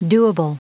Prononciation du mot doable en anglais (fichier audio)
Prononciation du mot : doable